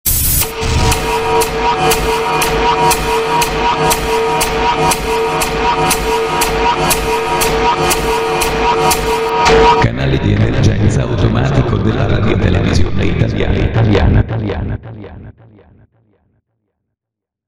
Italy Eas Alarm (1977) Sound Button - Free Download & Play